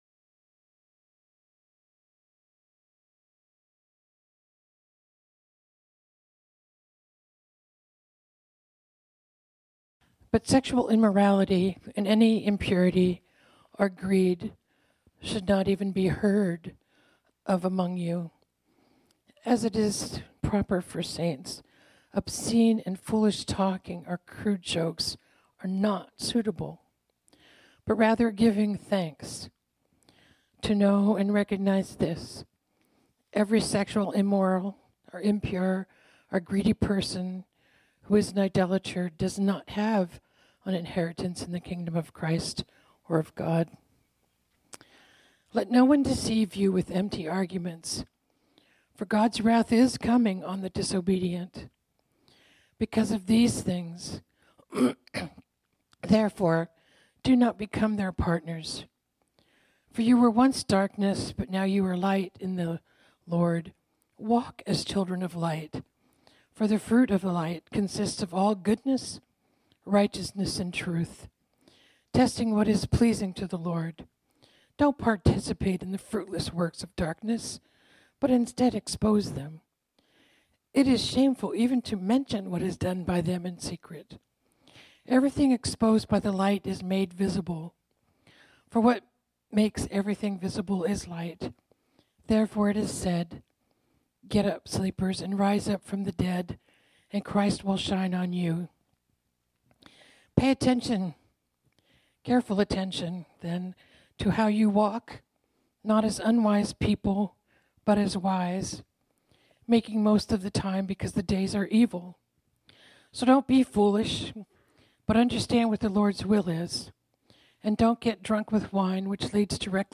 This sermon was originally preached on Sunday, November 12, 2023.